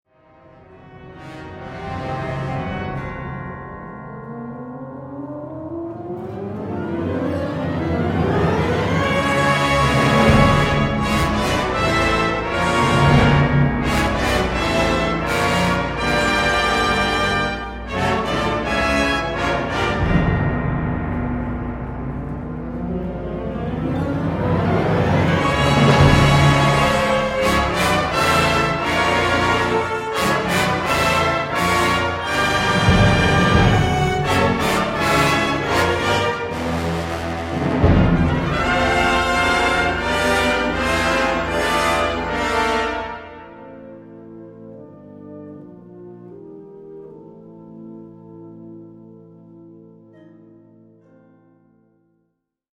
Concert Band Version
Key: F lydian mode